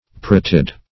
Parotid \Pa*rot"id\, n. (Anat.)